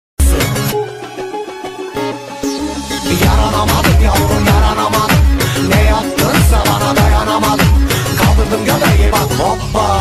intro-muzigitelifsiz.mp3